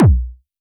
RDM_Copicat_SY1-Kick02.wav